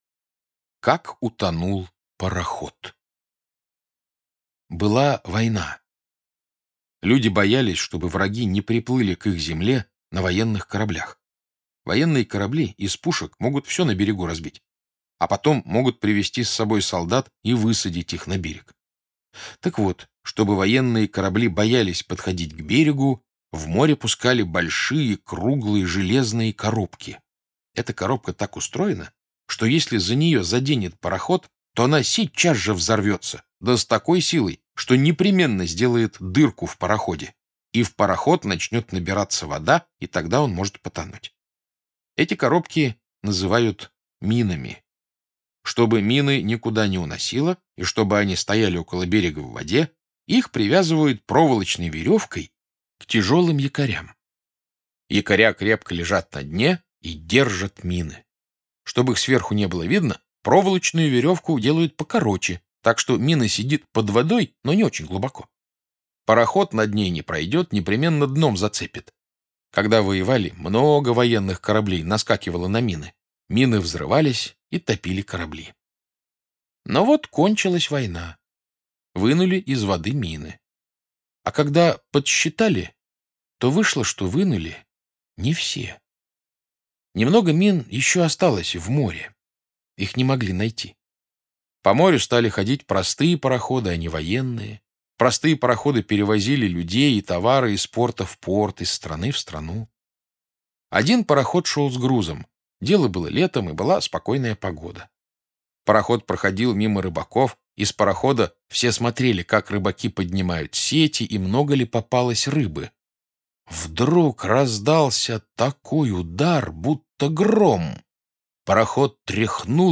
Аудиорассказ «Как утонул пароход»